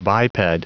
Prononciation du mot biped en anglais (fichier audio)
Prononciation du mot : biped